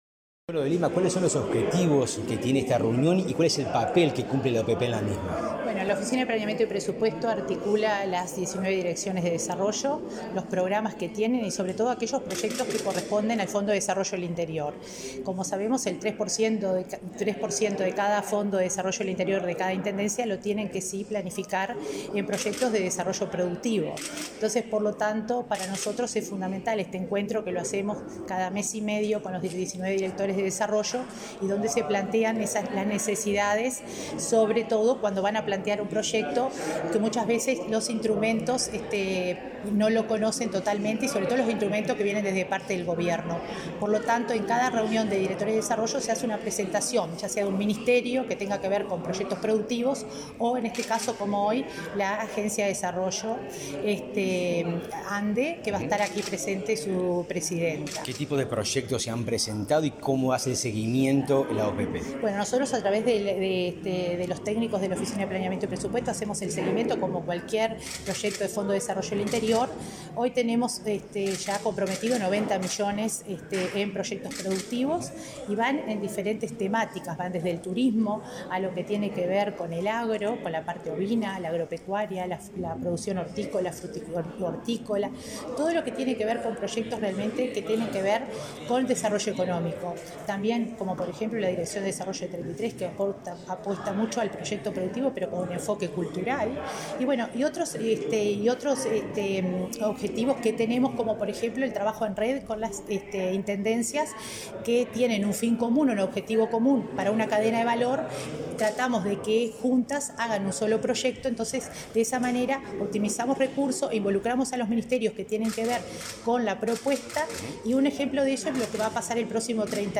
Declaraciones de la coordinadora de Descentralización de la OPP, María de Lima
Declaraciones de la coordinadora de Descentralización de la OPP, María de Lima 24/05/2022 Compartir Facebook X Copiar enlace WhatsApp LinkedIn Tras participar en la sesión de la Red de Directores de Desarrollo de los Gobiernos Departamentales, este 24 de mayo, en Florida, la coordinadora de Descentralización de la Oficina de Planeamiento y Presupuesto (OPP), María de Lima, efectuó declaraciones a Comunicación Presidencial.